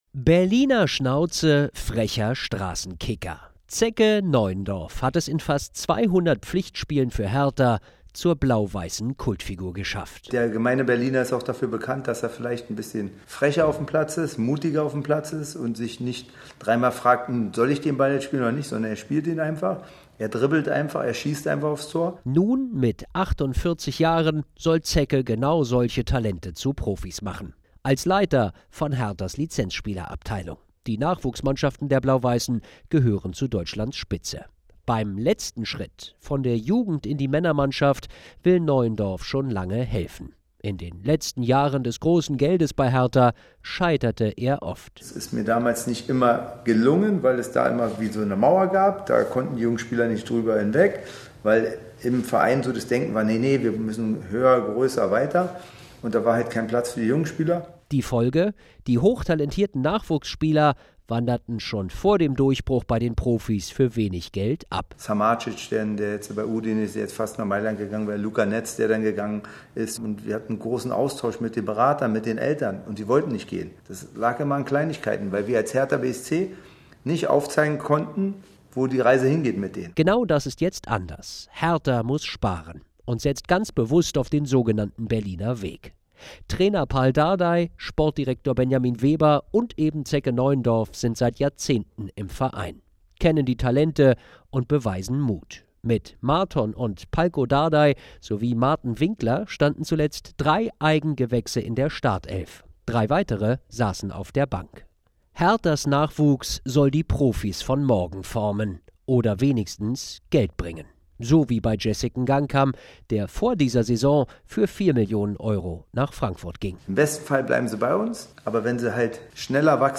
Inforadio Nachrichten, 13.09.2023, 19:00 Uhr - 13.09.2023